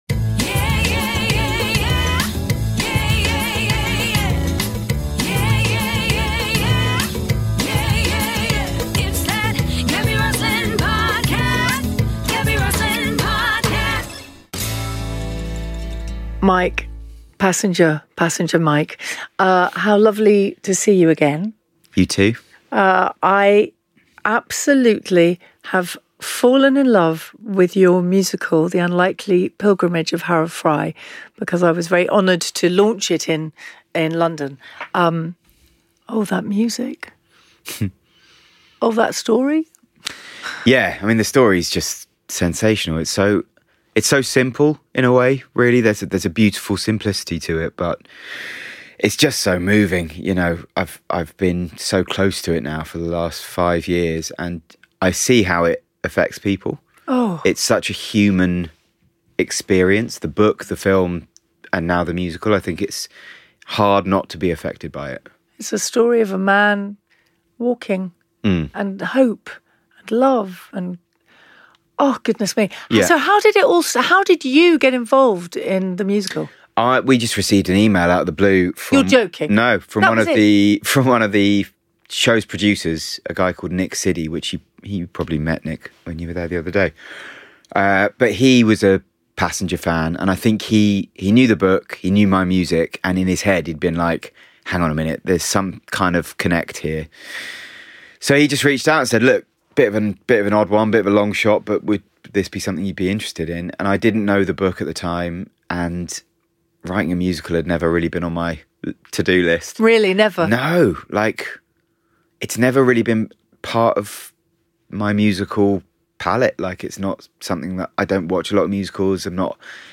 Passenger (aka Mike Rosenberg) joins Gaby for a chat about 'The Unlikely Pilgrimage Of Harold Fry' - a new musical which he has composed.
They chat about the magic of songwriting, how busking taught Mike his craft, and why being an independent artist is so freeing. He also treats us to a live performance of one of the songs from his new musical!